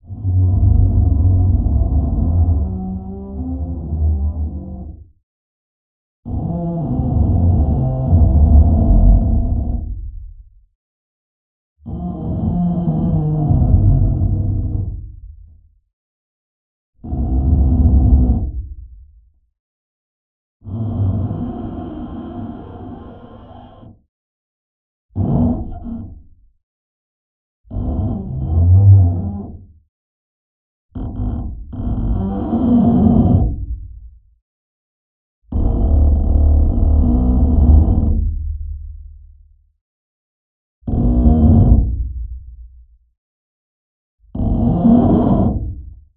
Originally designed for seismic measurements, it can be used with regular field recording equipment to capture very faint vibrations in various materials and even soil.
bowed-plastic-siding-geophone.mp3